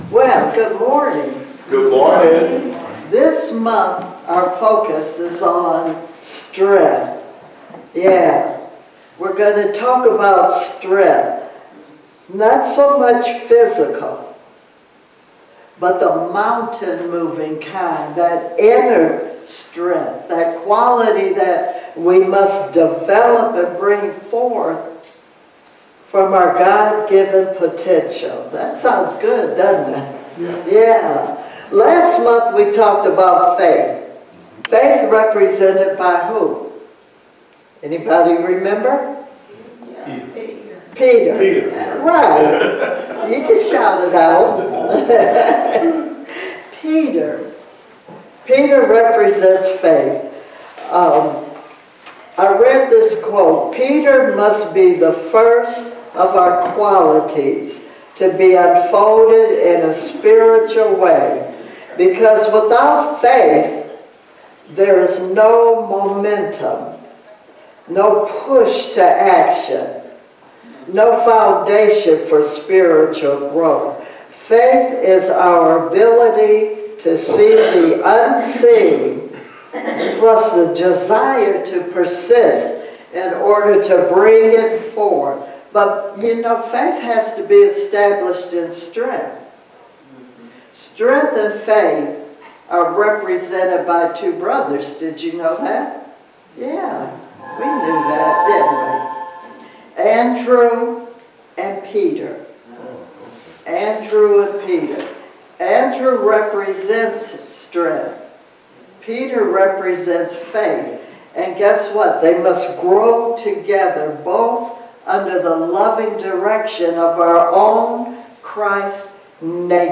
Spiritual Leader Series: Sermons 2025 Date